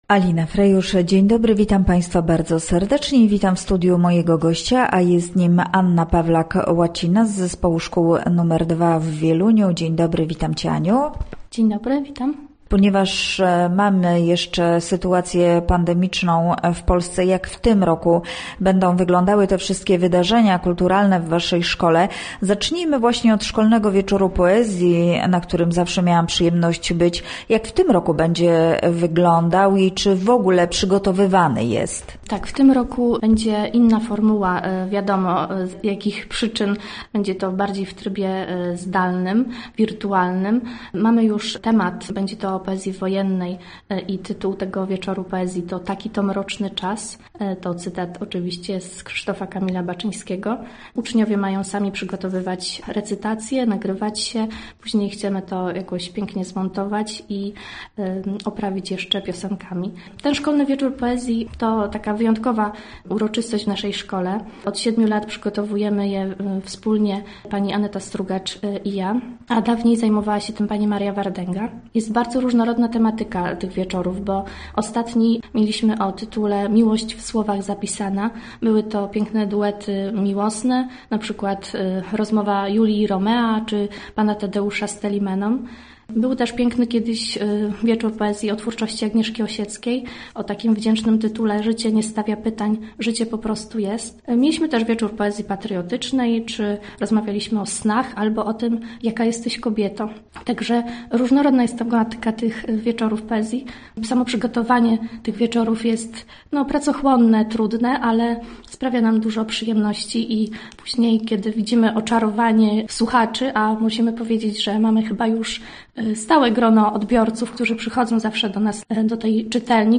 Nasz gość mówi o tym i innych kulturalnych wydarzeniach w szkole.